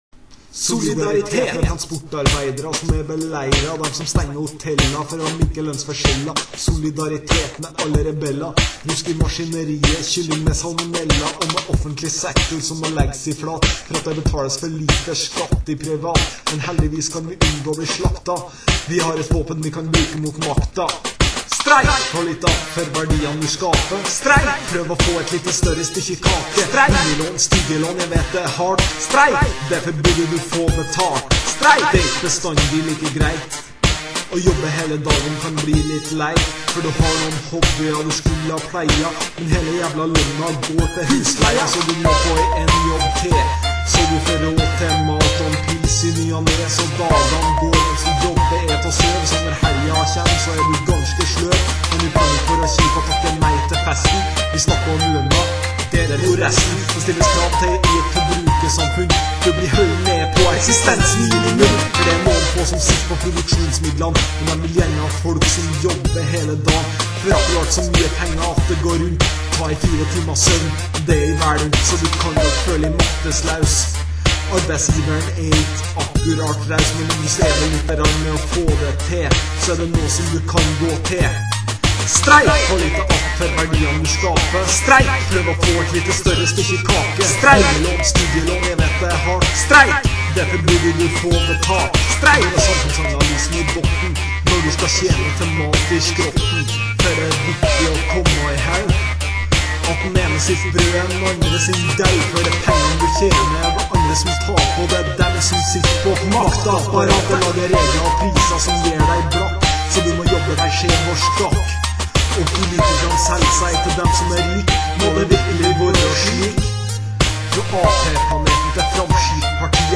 Funky Hip Hop beats